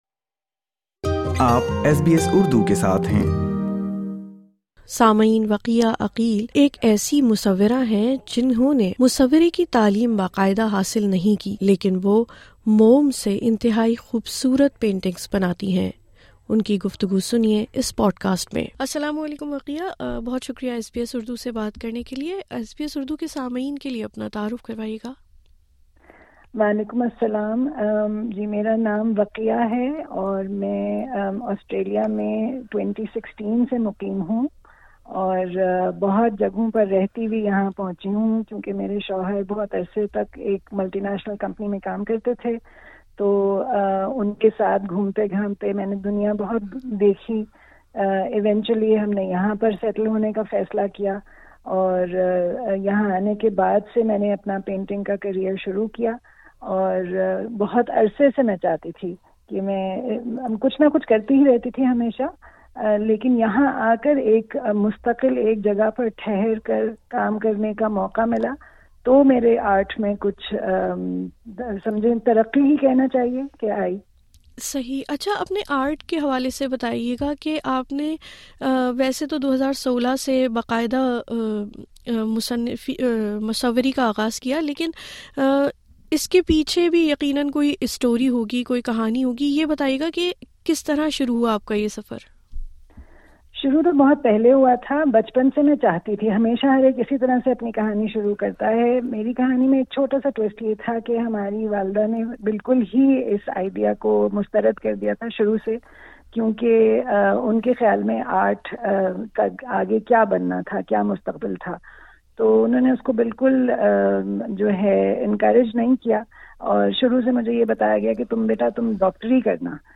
رنگوں کو کینوس پر مختلف انداز میں بکھیرنے والی مصورہ کی ایس بی ایس اردو سے گفتگو سنئیے اس پوڈ کاسٹ میں